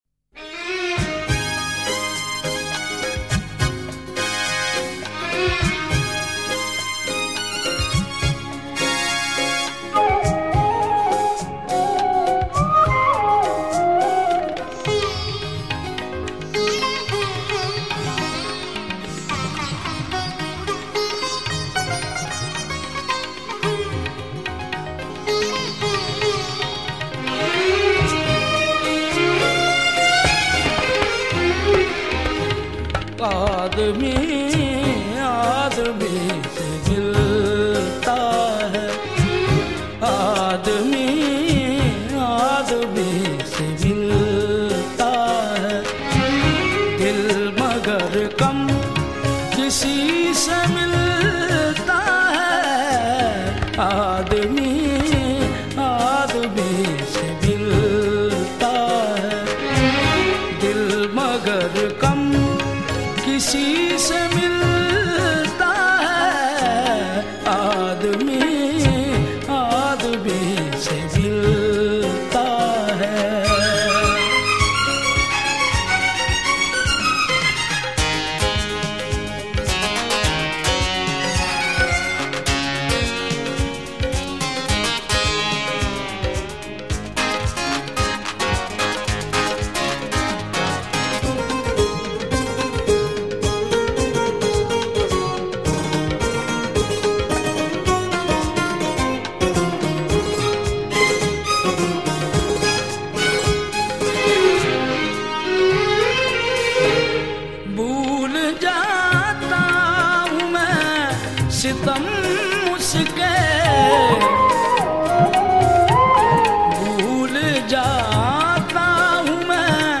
a traditional style of qawwali